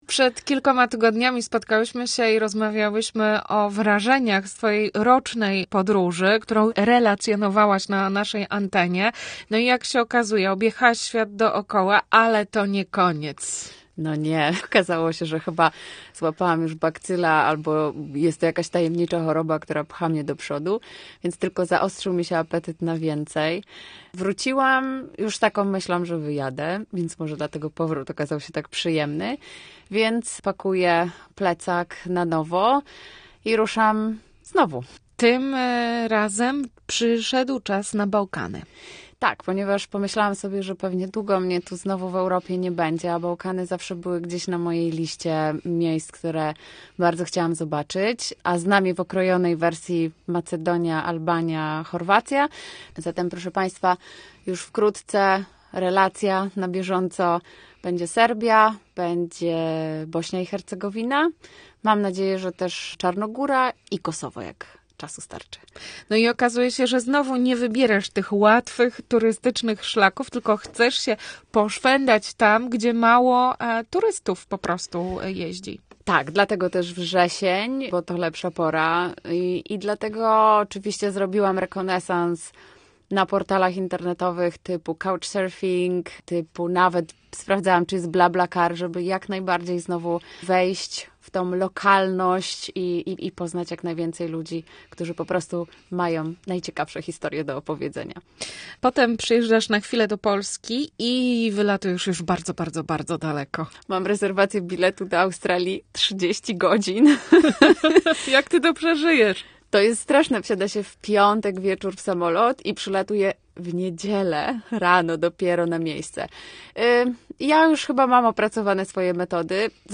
[ROZMOWA] - Radio Łódź